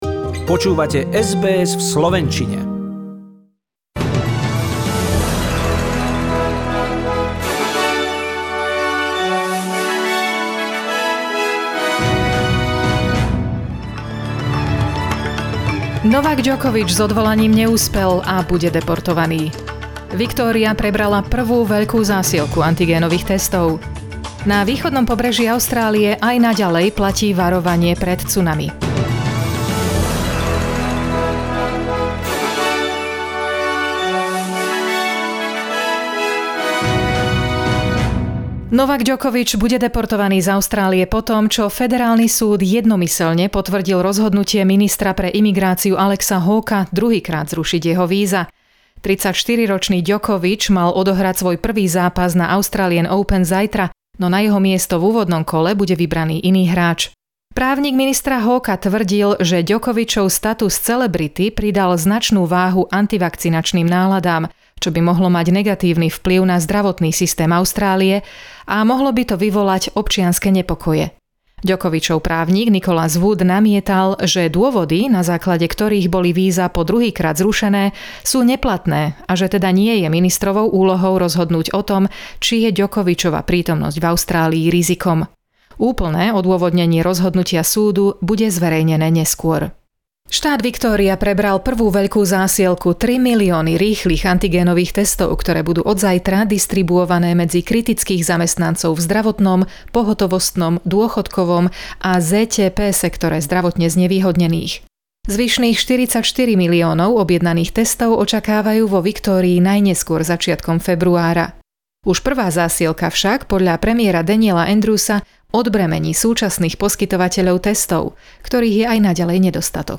SBS NEWS - správy v slovenčine